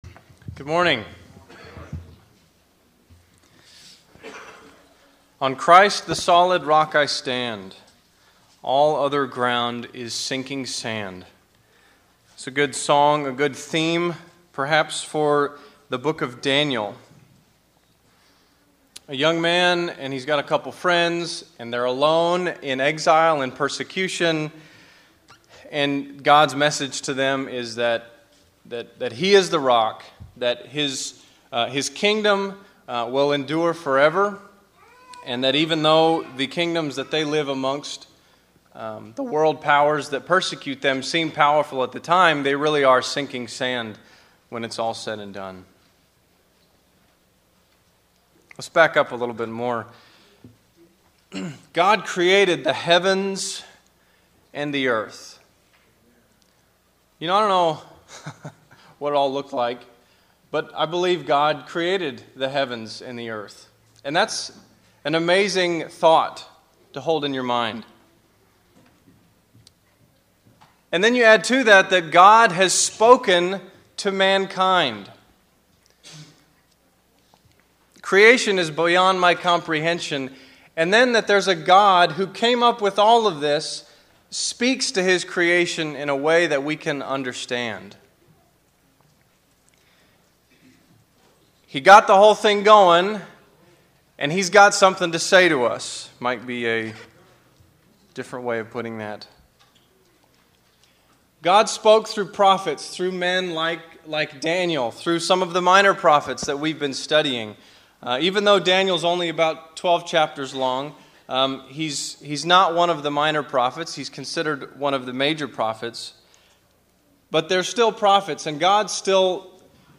Valley church of Christ - Matanuska-Susitna Valley Alaska
Sermons